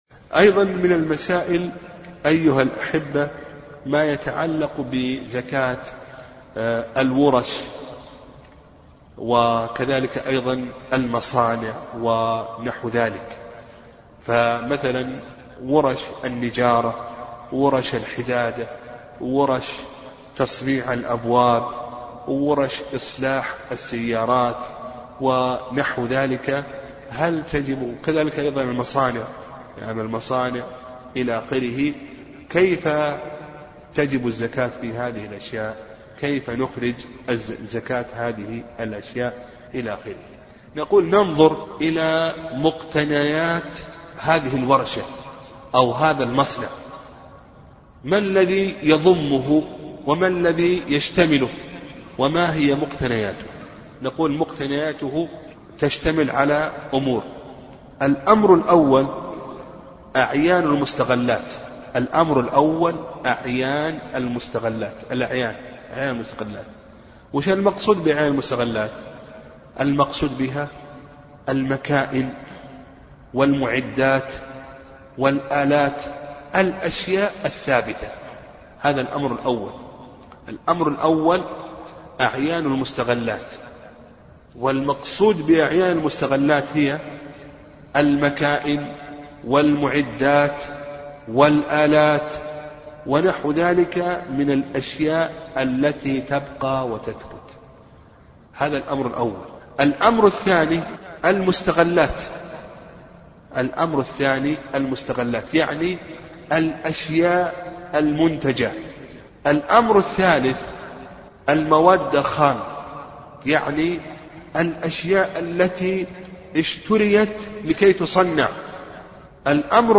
أرشيف صوتي لدروس وخطب ومحاضرات